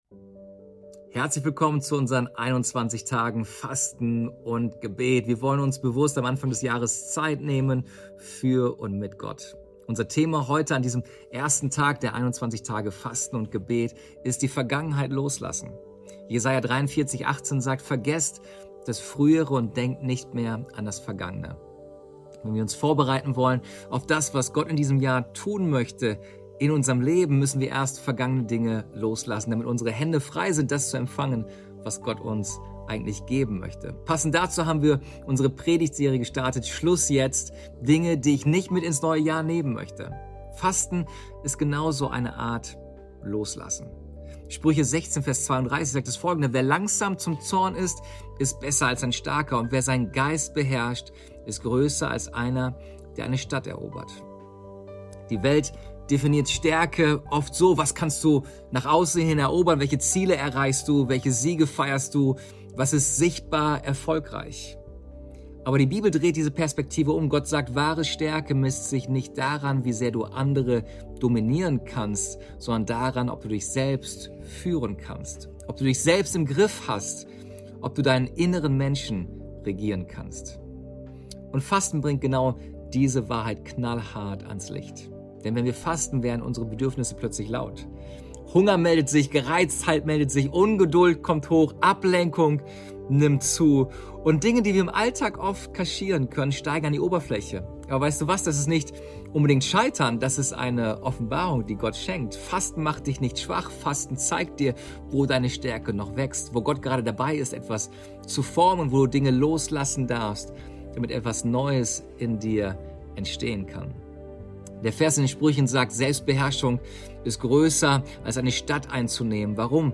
Tag 1 der Andacht zu unseren 21 Tagen Fasten & Gebet